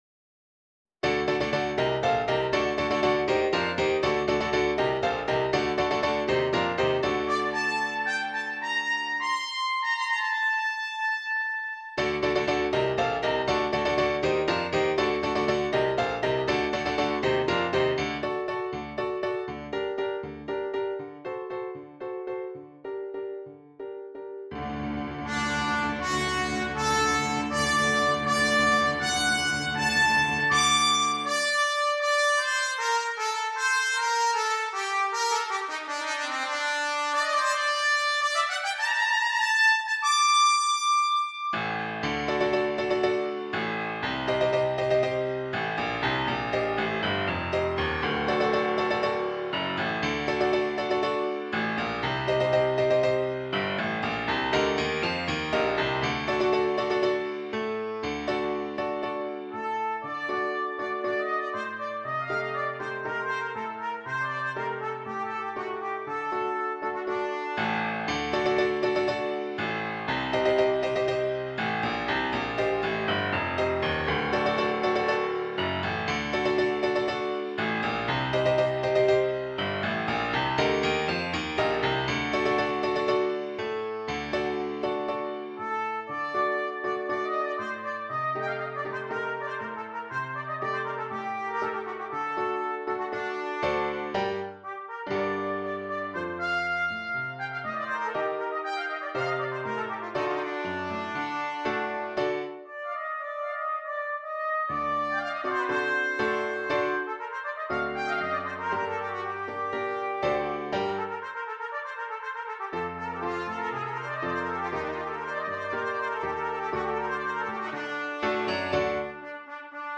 Trumpet and Piano
Traditional
traditional bullfighting song
features a short cadenza and some requisite high notes